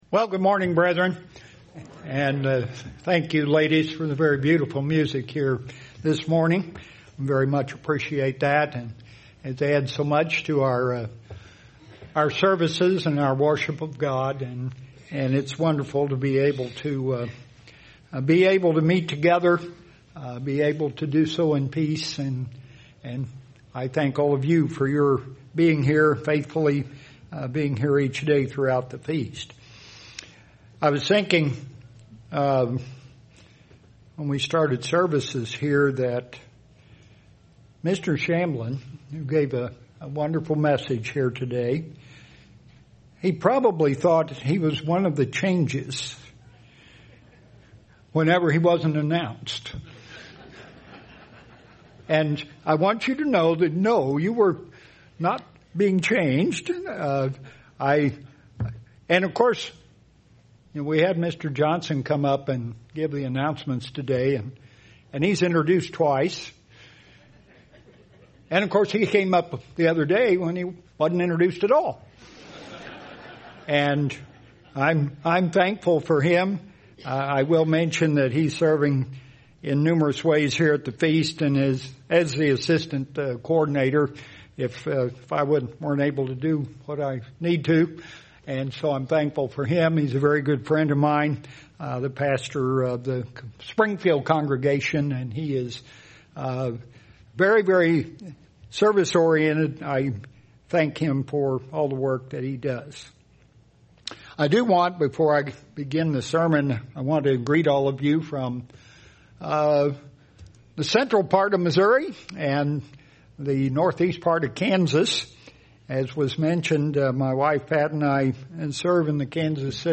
This sermon was given at the Branson, Missouri 2023 Feast site.